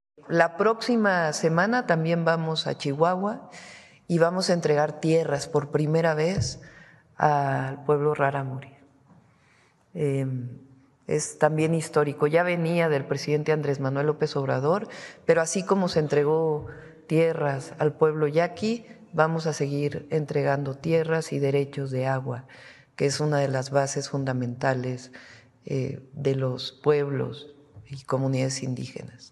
Durante su conferencia de prensa matutina, Sheinbaum Pardo indicó que su paso por Chihuahua forma parte de un plan para reparar injusticias históricas hacia los pueblos originarios.